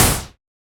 RDM_TapeA_SY1-Snr01.wav